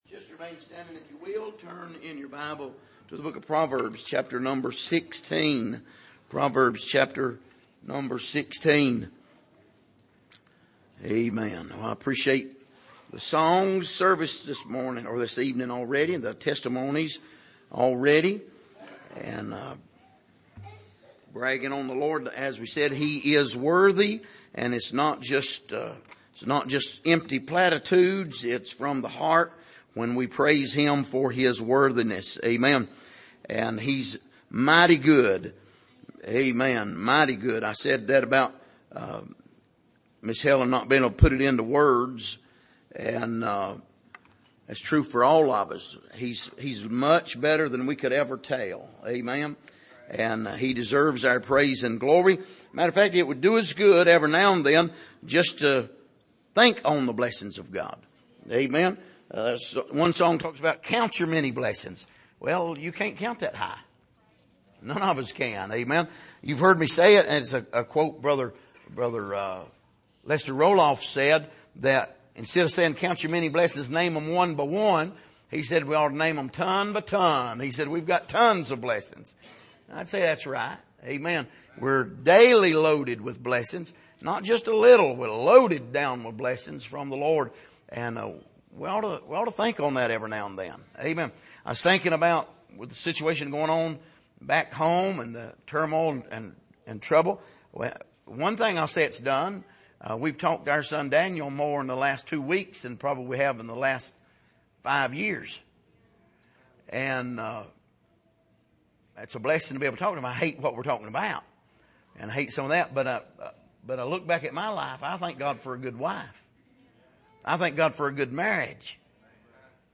Passage: Proverbs 16:18-25 Service: Sunday Evening